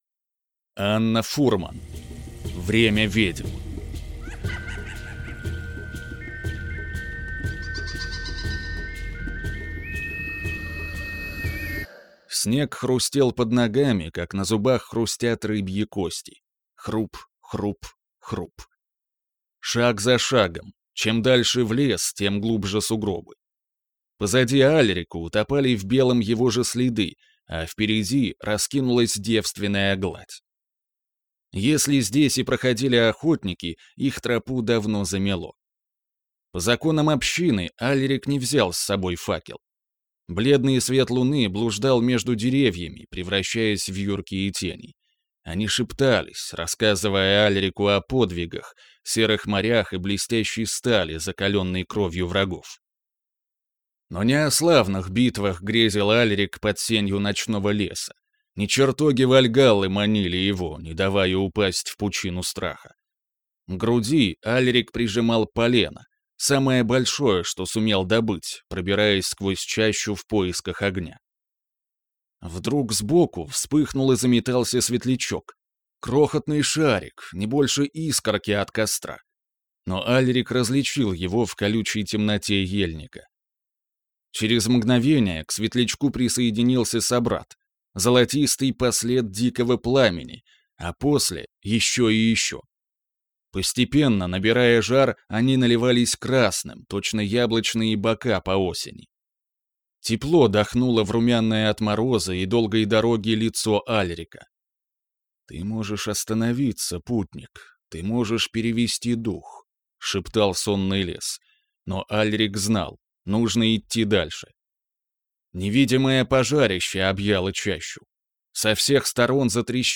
Aудиокнига Время ведьм